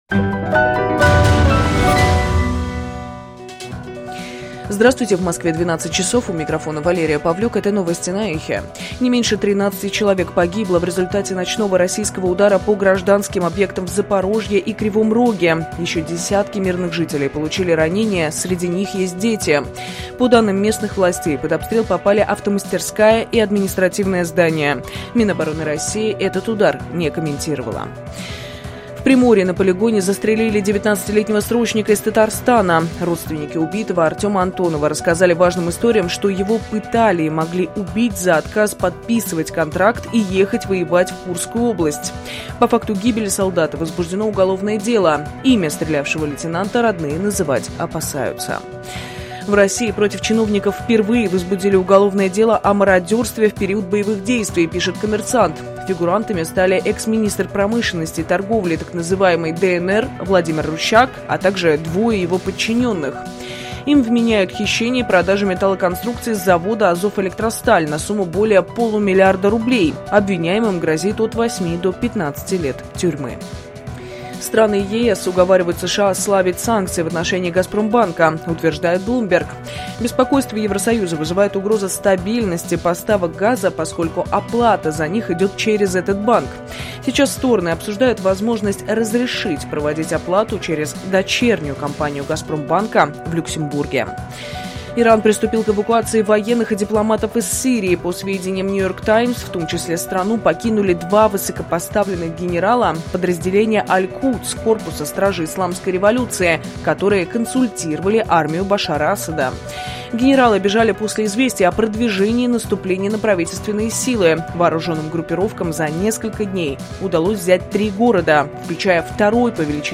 Свежий выпуск новостей.